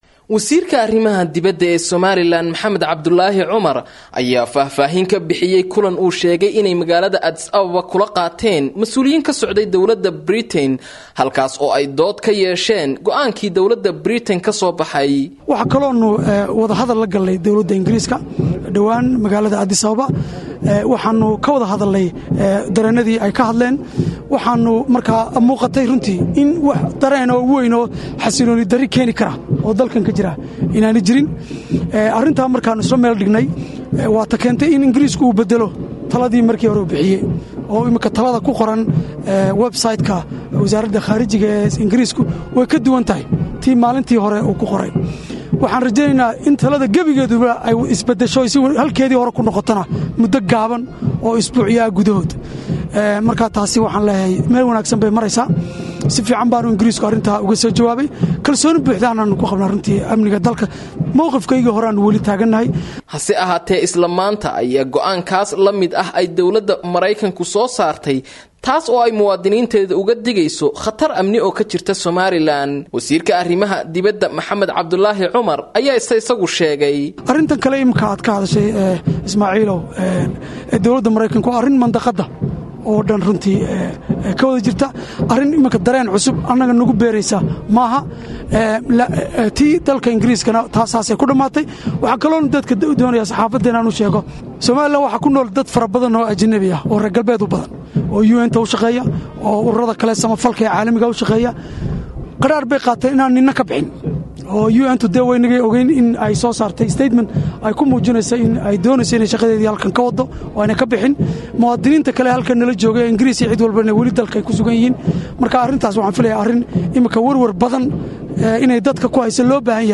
Dhageyso warbixinta Jawaabta Somaliland